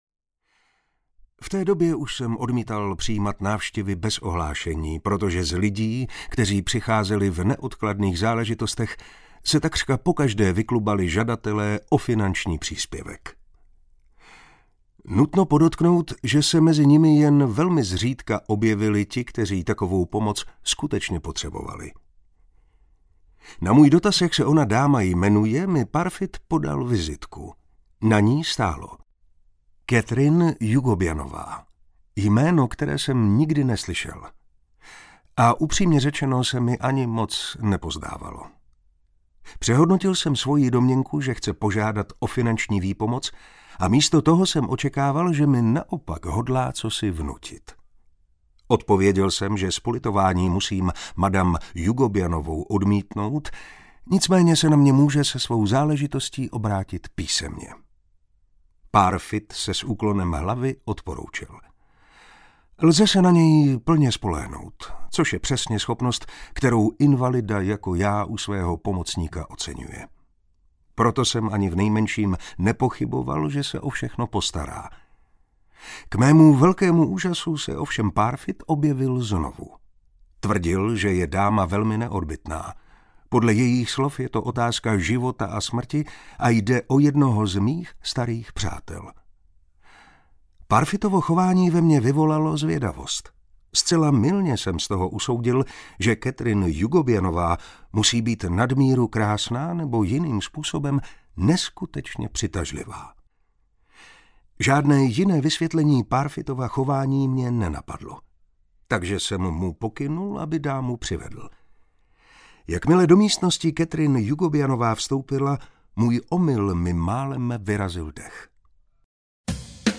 Růže a tis audiokniha
Ukázka z knihy
• InterpretMartin Preiss